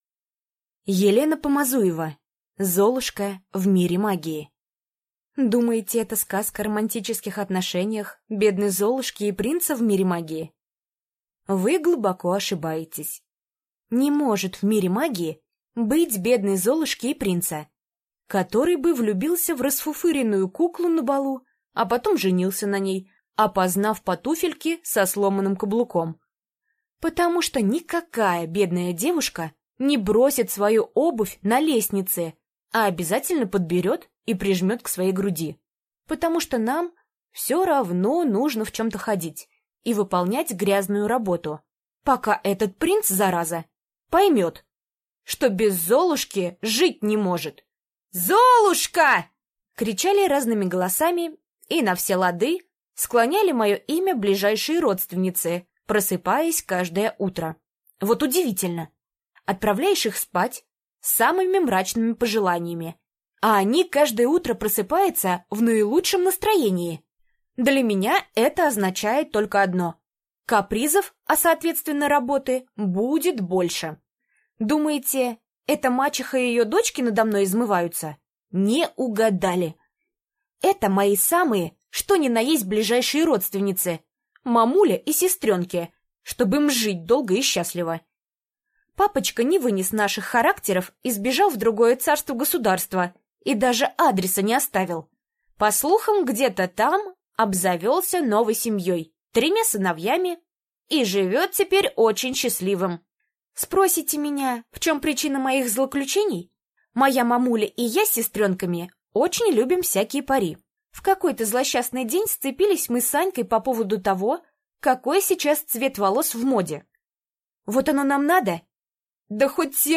Аудиокнига Золушка в мире магии | Библиотека аудиокниг